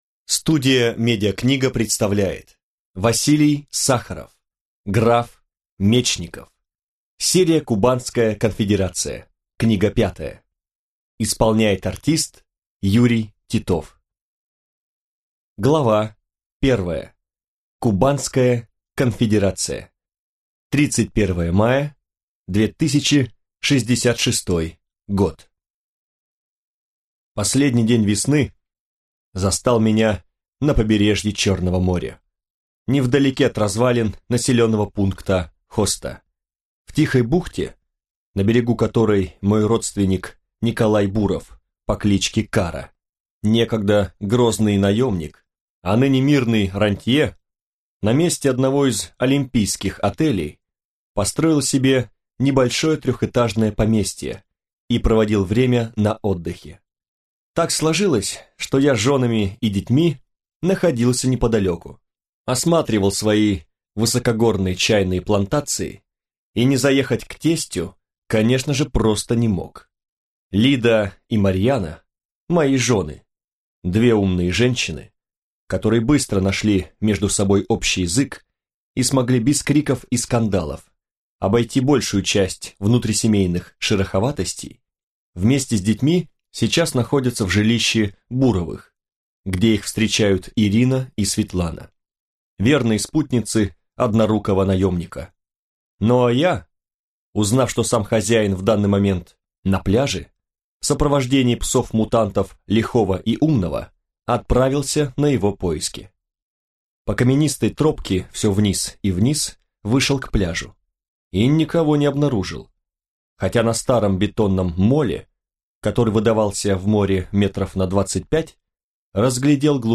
Аудиокнига Граф Мечников | Библиотека аудиокниг